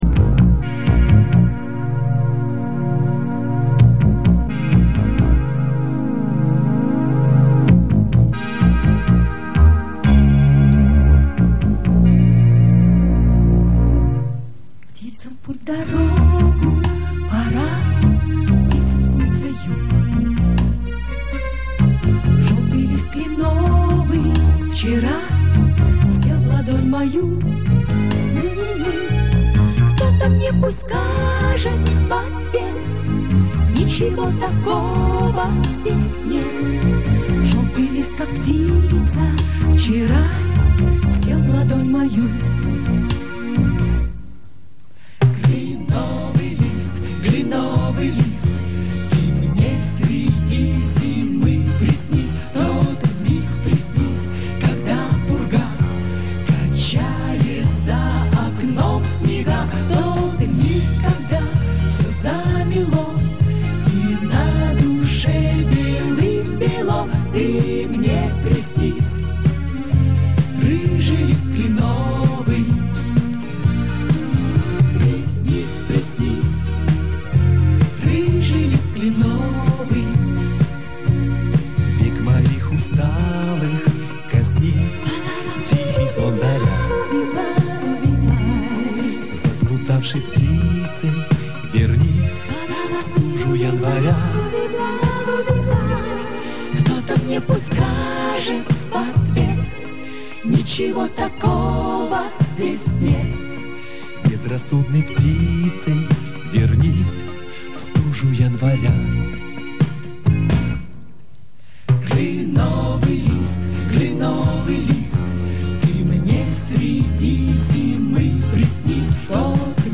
НЕ ОЧЕНЬ КАЧЕСТВЕННАЯ ЗАПИСЬ
вокальный квартет